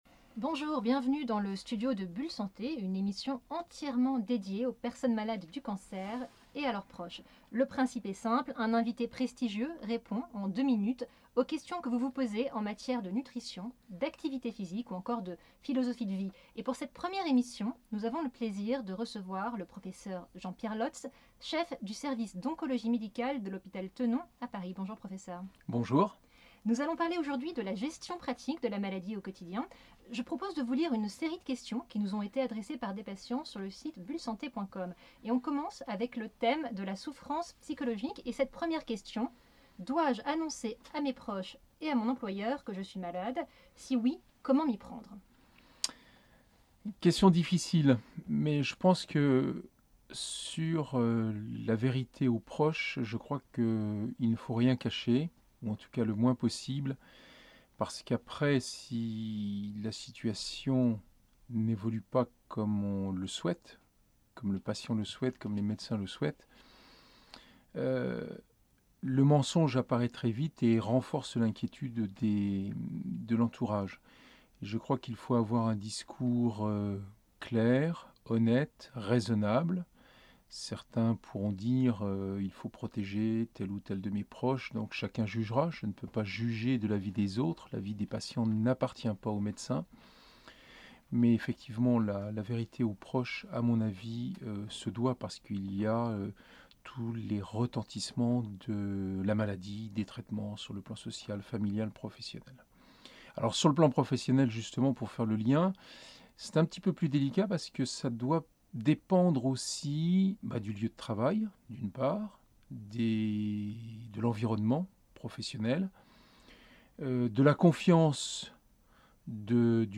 L’intégralité de son interview figure dans le podcast Bulle Santé ci-dessous.